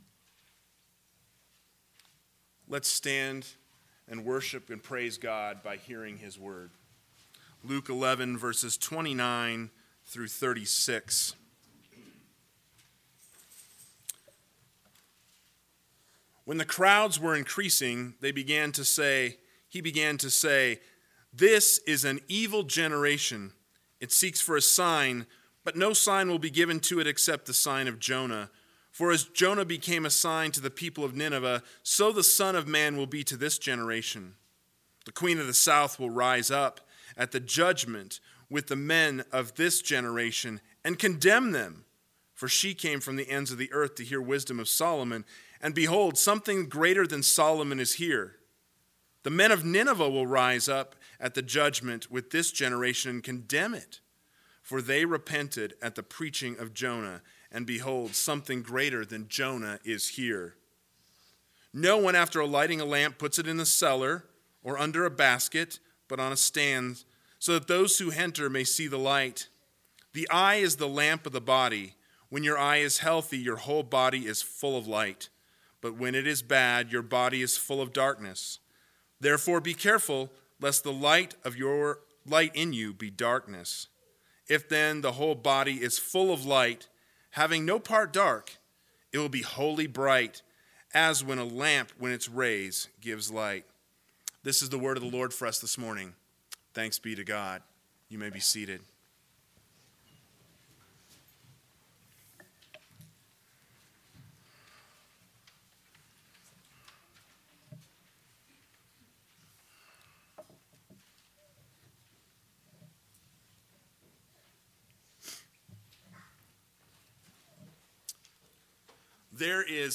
AM Sermon – 08/23/2020 – Luke 11:29-36 – Have You Seen the Light?